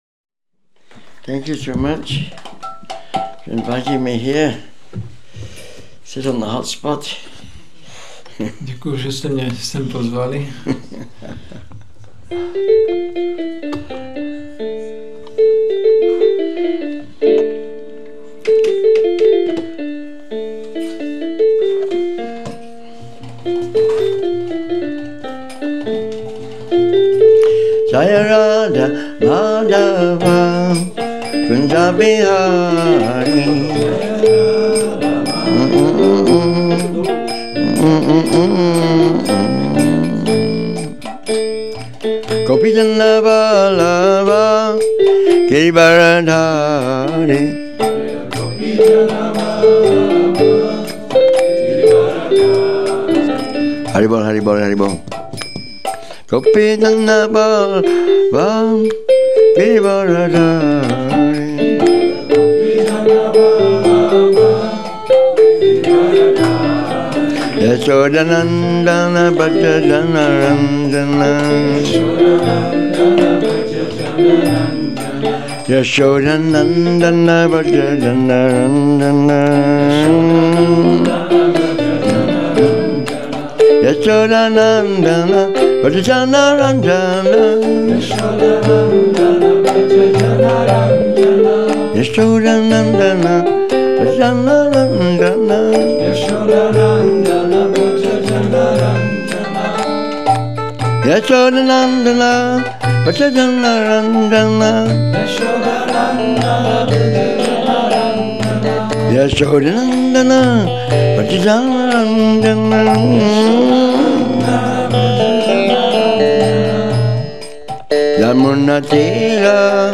Přednáška SB-11.7.19